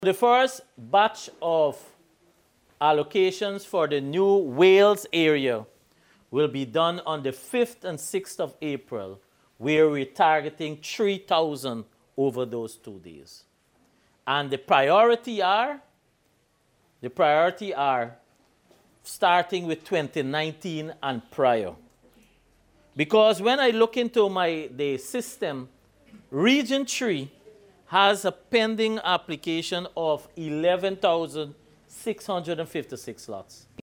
Minister Collin Croal revealed this development during a community meeting at Anna Catherina on the West Coast of Demerara.